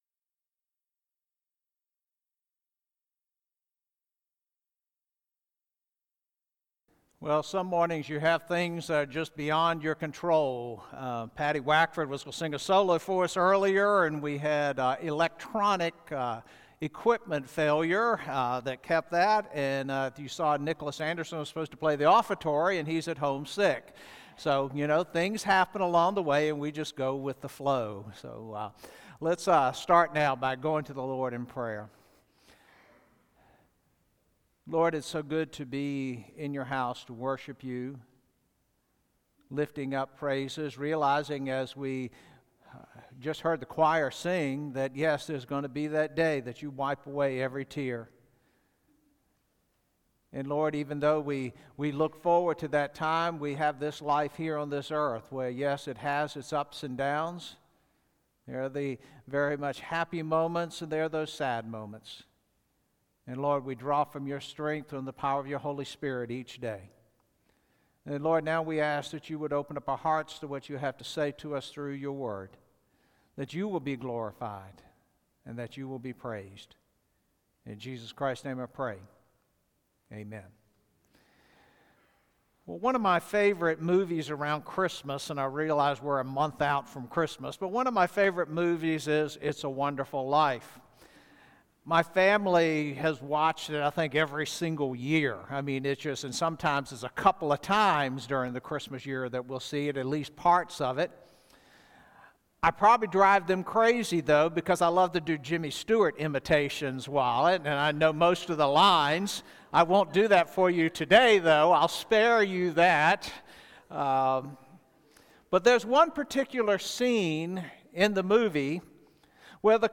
Sermons | Calvary Baptist Bel Air